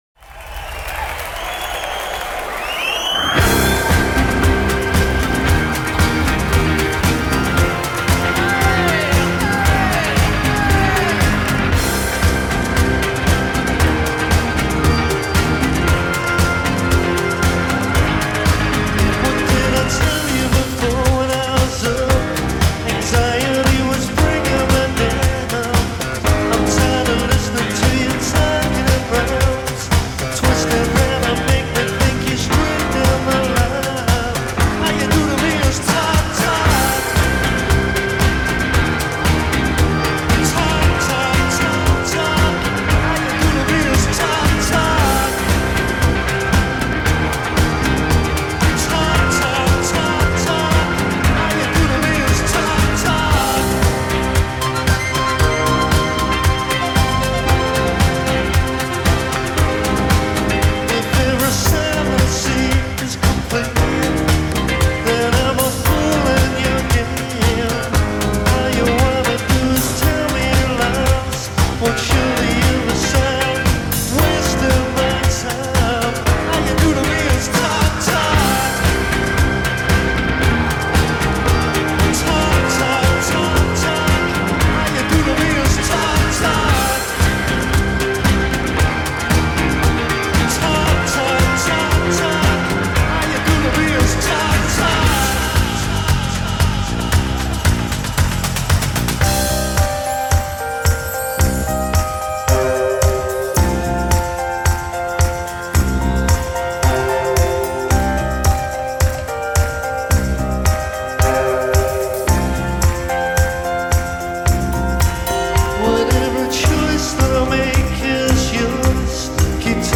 vocals/main songwriter
drums
bass guitar
keyboards